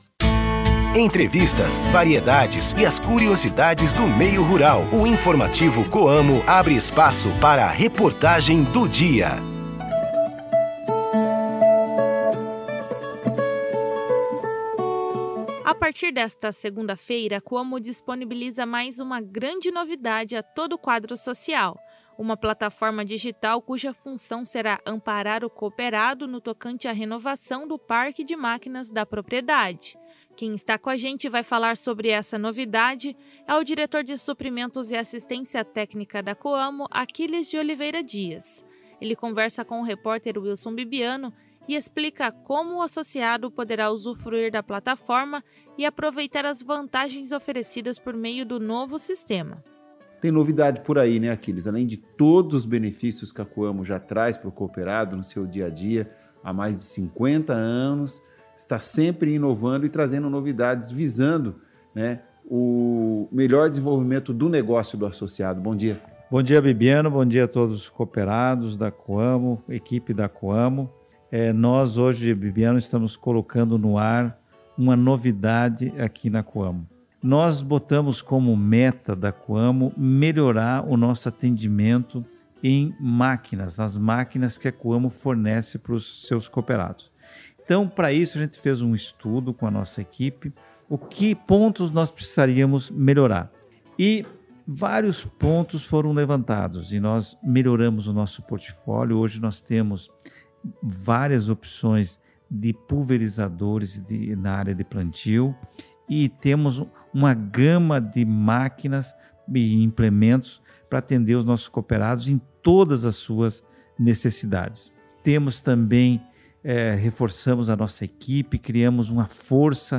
Produzido pela Assessoria de Comunicação da Cooperativa, o Informativo Coamo vai ao ar de segunda-feira a sábado e é disponibilizado no site da Coamo e veiculado em 32 de emissoras de rádios do Paraná, Santa Catarina e Mato Grosso do Sul.